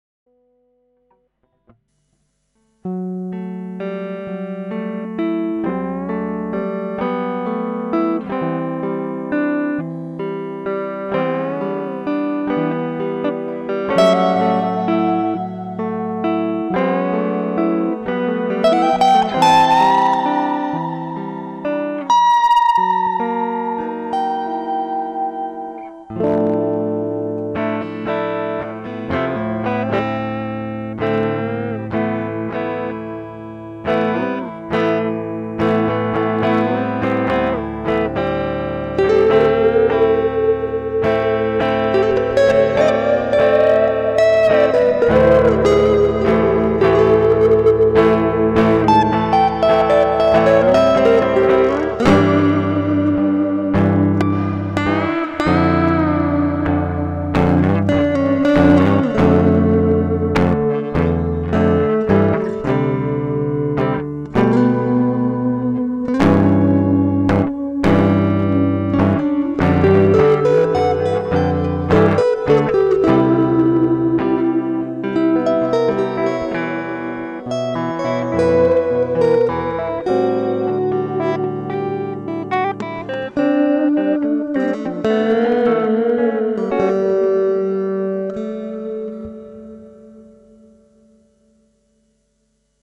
acoustjam.mp3
Typically a metalshredderhead but this is todays 2 inspired jams got me to hit rec while practicing fingerstyle.
No editing magic so rough I've just started using reaper but i think it's salvageable.